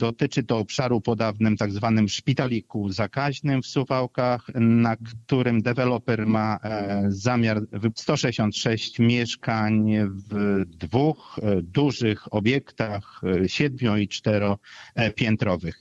O szczegółach mówił w piątek (22.05) w Radiu 5 Zdzisław Przełomiec, przewodniczący Rady Miejskiej.